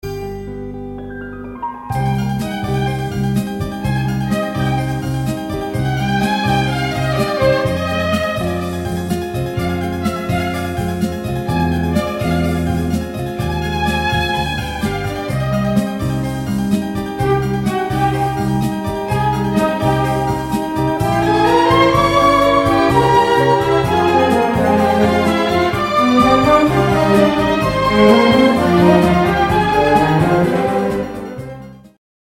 • Качество: 128, Stereo
оркестр